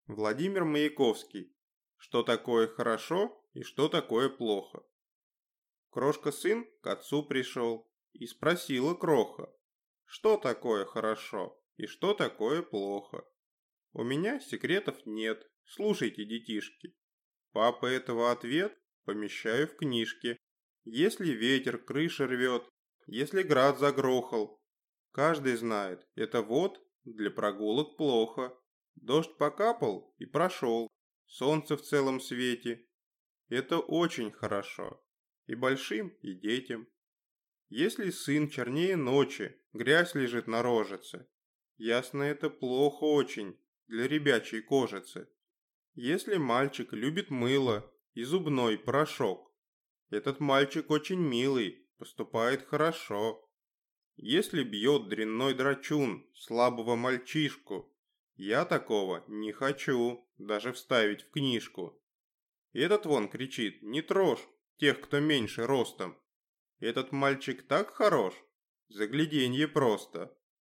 Аудиокнига Что такое хорошо и что такое плохо?